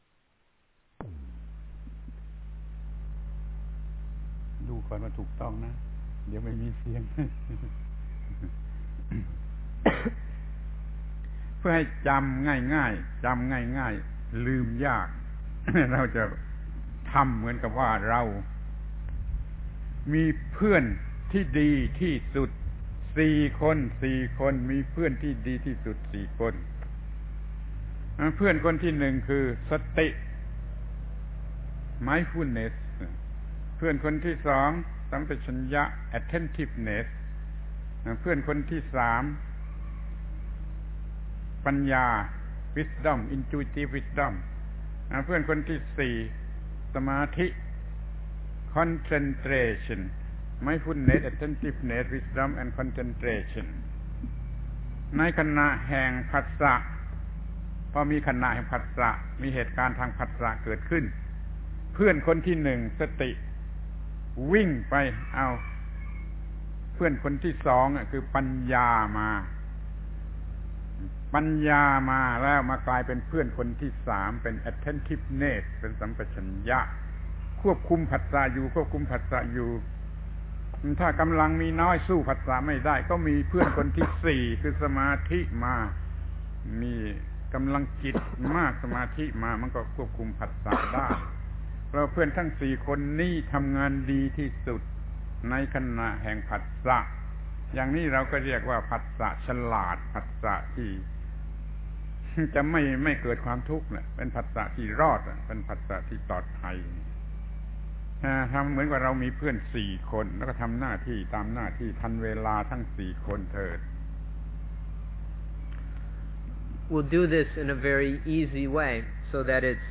พระธรรมโกศาจารย์ (พุทธทาสภิกขุ) - อบรมชาวต่างประเทศ อบรมผู้ปฏิบัติจิตตภาวนา 2531 ชีวิตใหม่ (ต่อ) แผ่น 2